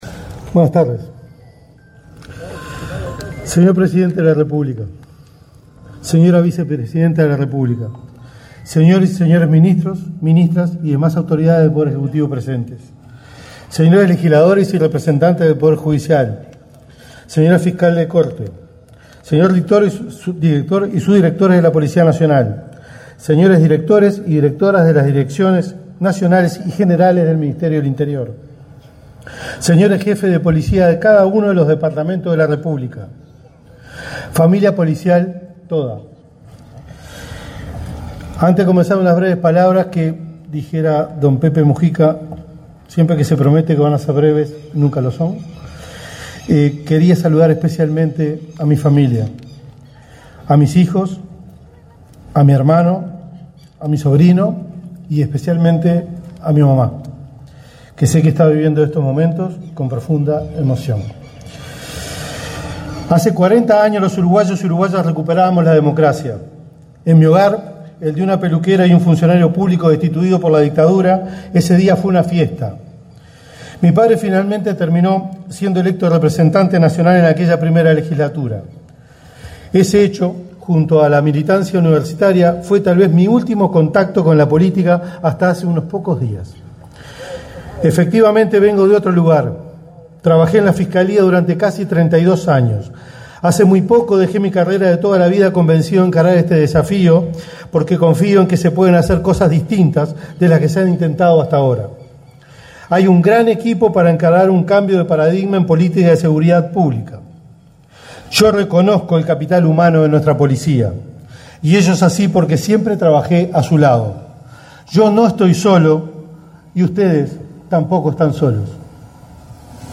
Palabras del ministro del Interior, Carlos Negro
Palabras del ministro del Interior, Carlos Negro 05/03/2025 Compartir Facebook X Copiar enlace WhatsApp LinkedIn El presidente de la República, Yamandú Orsi, y la vicepresidenta, Carolina Cosse, participaron, este 5 de marzo, en la ceremonia de asunción de las autoridades del Ministerio del Interior. Carlos Negro asumió como ministro y Gabriela Valverde, como subsecretaria.